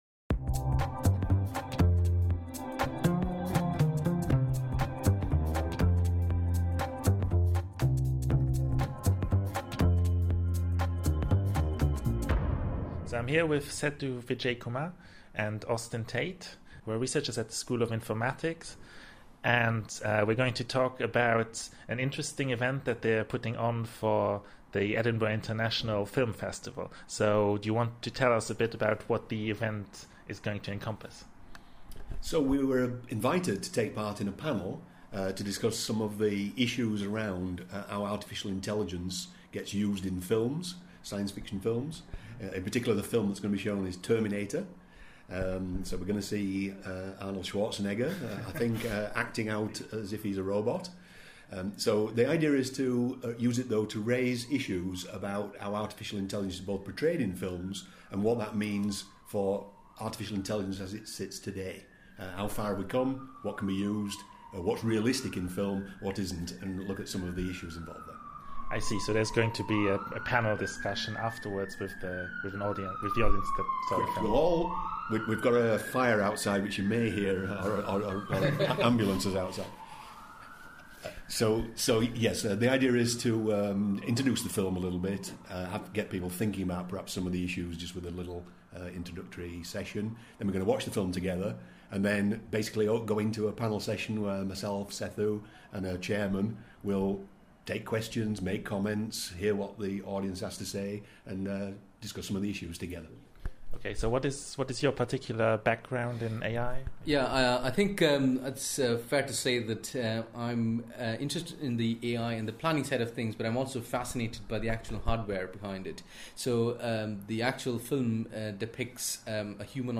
EUSci: Podcast Extra: A Conversation with AI Researchers